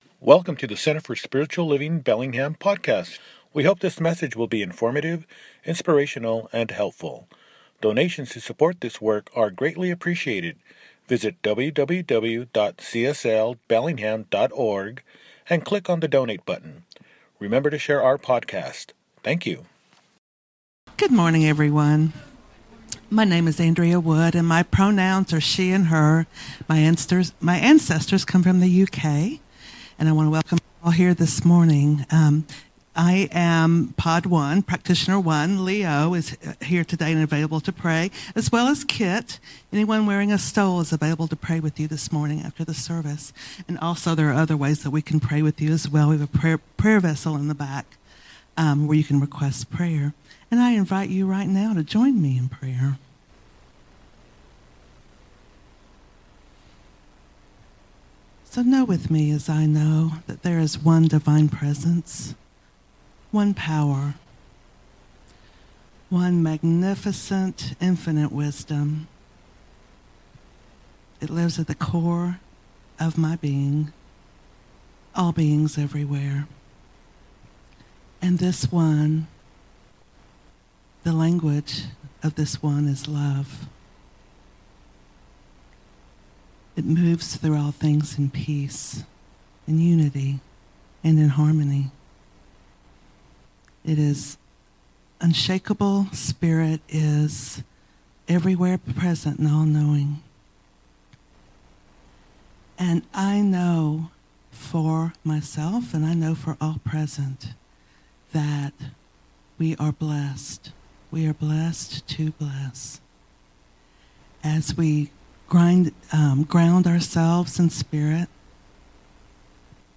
The Whole Field Is Alive: Creating Together with the Infinite – Celebration Service